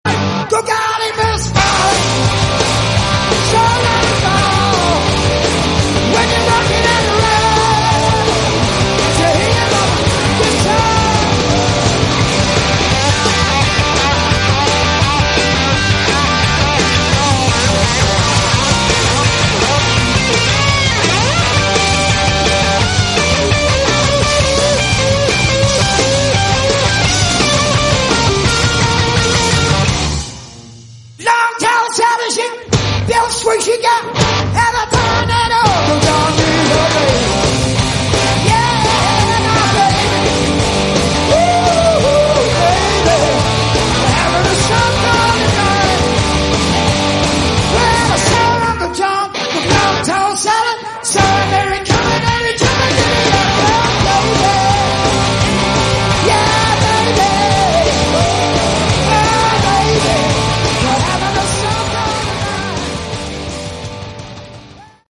Category: Hard Rock
drums
bass
lead guitar